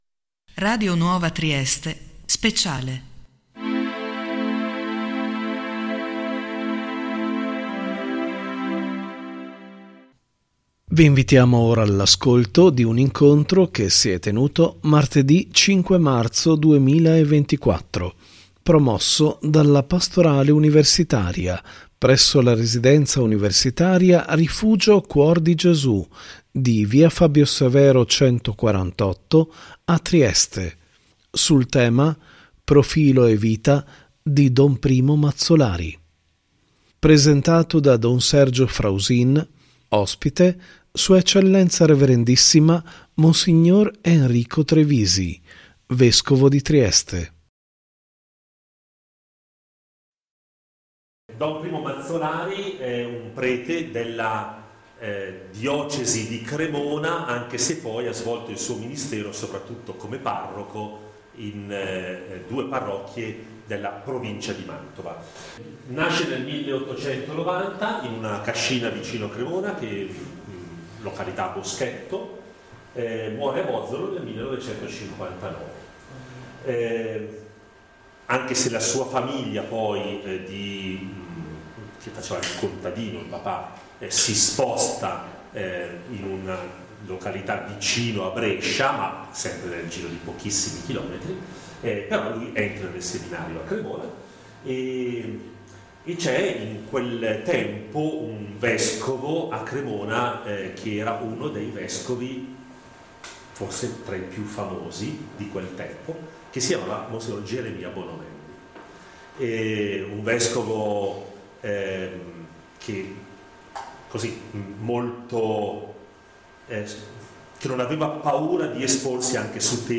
ospite: S.E. Rev.ssima Mons. Enrico Trevisi, Vescovo di Trieste.